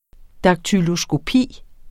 Udtale [ dɑgtylosgoˈpiˀ ]